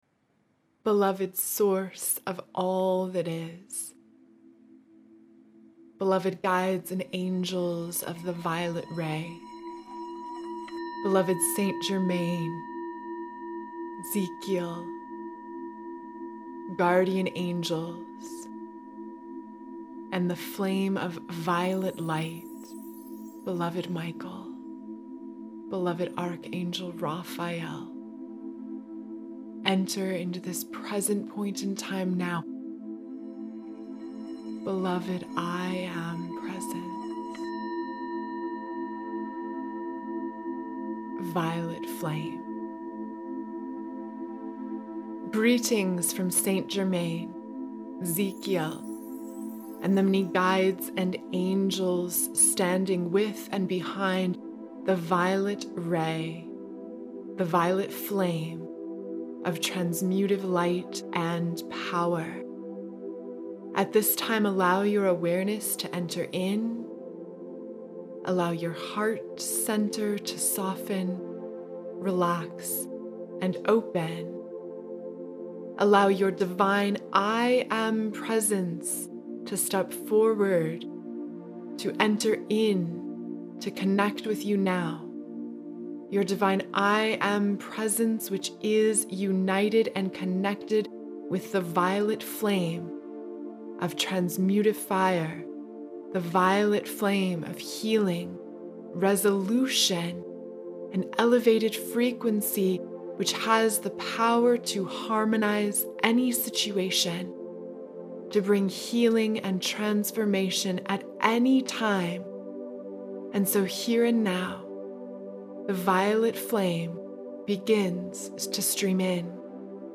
Violet Flame Meditation